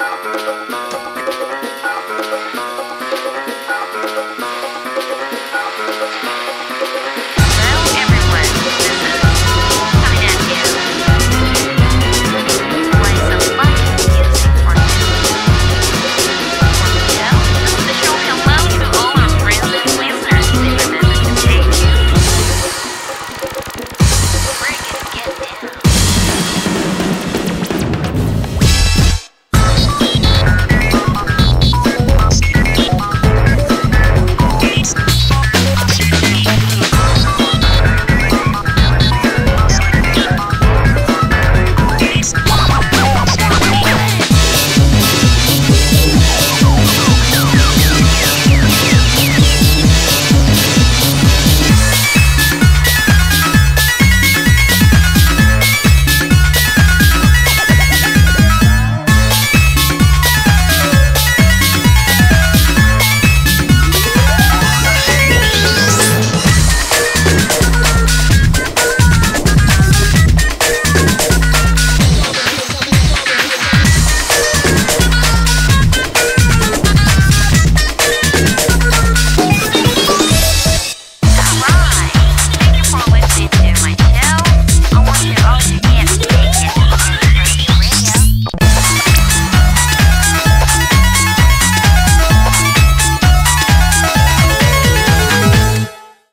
BPM: 130